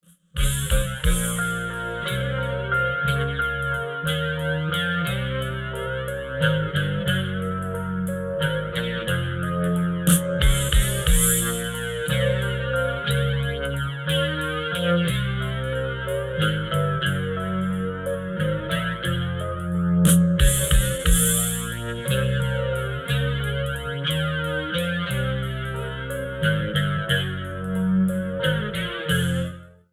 instrumental backing track cover
Backing Tracks for Karaoke, Accompaniment